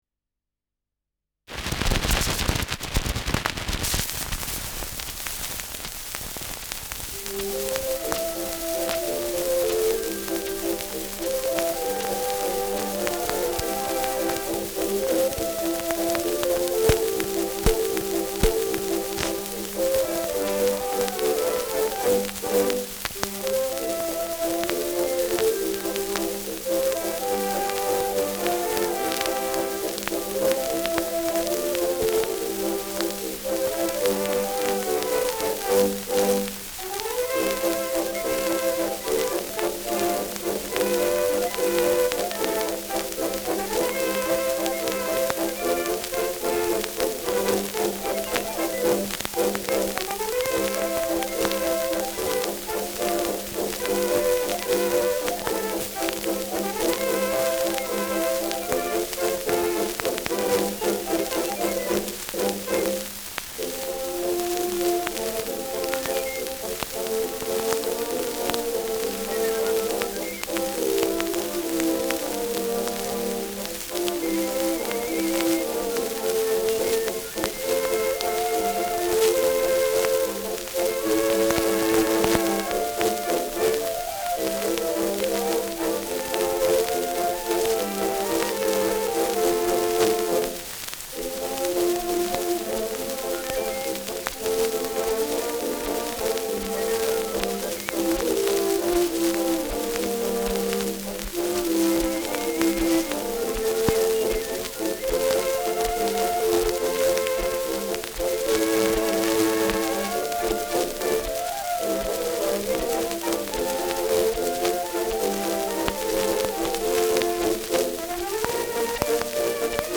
Schellackplatte
Stark abgespielt : Erhöhtes Grundrauschen : Häufiges Knacken : Nadelgeräusch : Hängt zwischen 0’17“ und 0’20“
[unbekanntes Ensemble] (Interpretation)